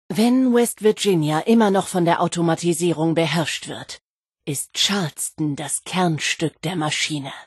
Audiodialoge